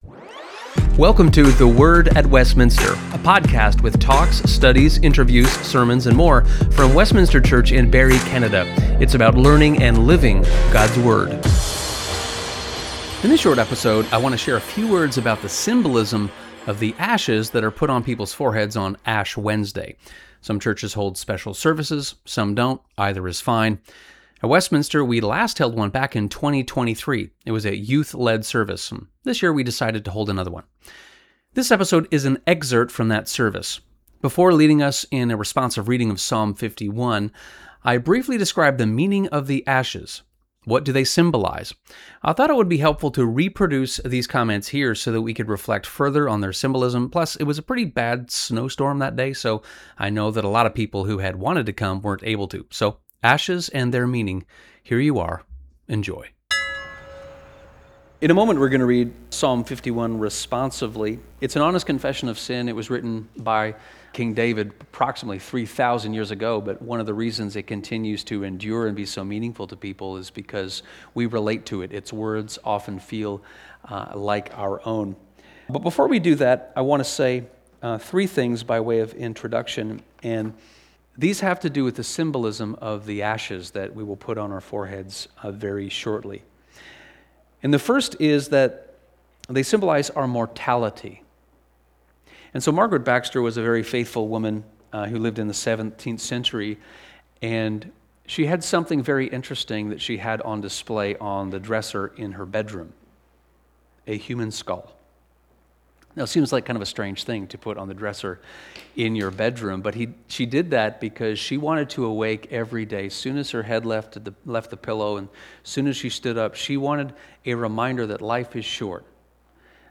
It is an exert from the worship service on Wednseday, February 18, 2026.